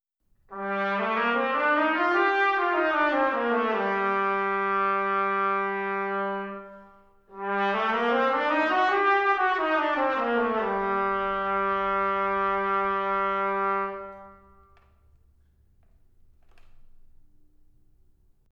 Méthode pour Trompette ou cornet